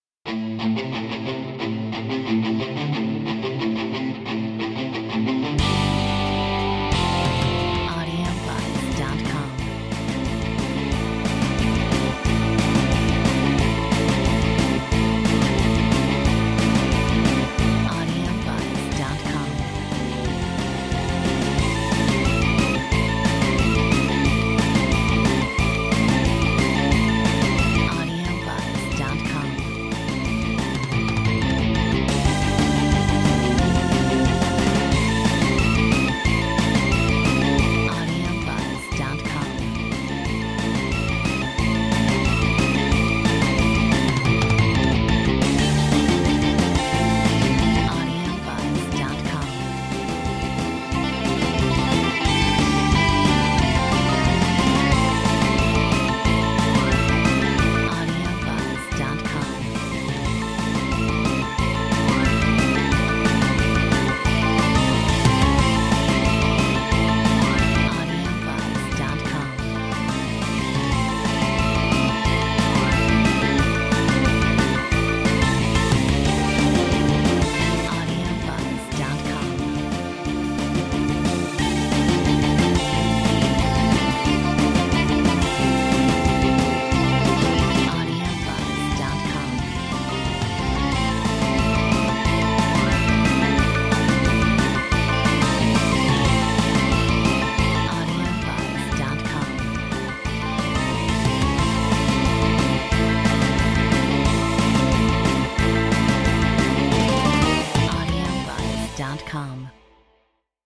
Metronome 180